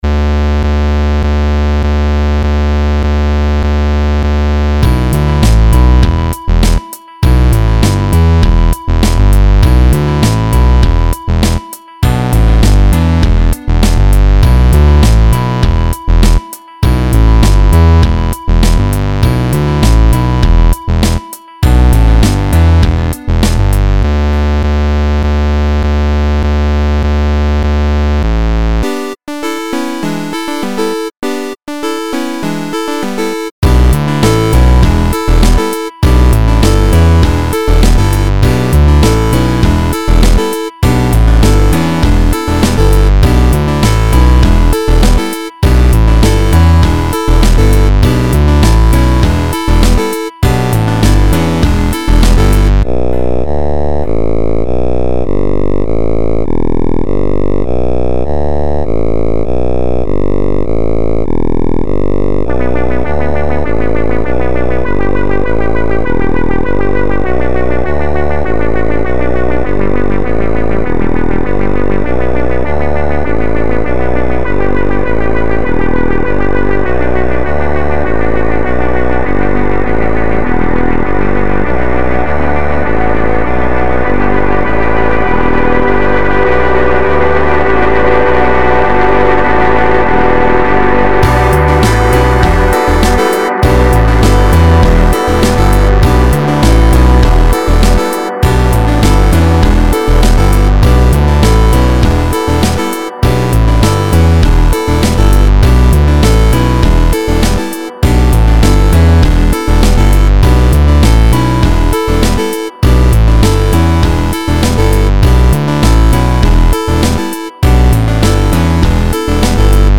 Filed under: Instrumental Song | Comments (5)
This beat is bangin!